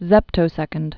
(zĕptō-sĕkənd)